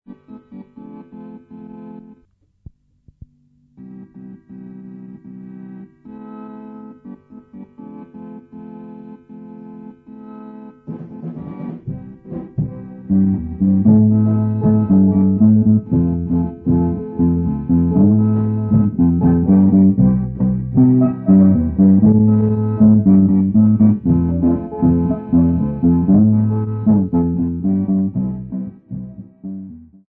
Folk music -- South Africa
Drum
Organ music
Sub-Saharan African music
field recordings
Instrumental music with organ and drum accompaniment